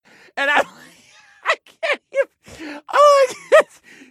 laughter_02